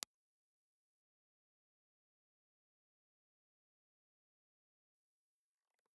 Impulse Response file of the Toshiba B ribbon microphone with HPF position 4
Toshiba_B_HPF4_IR.wav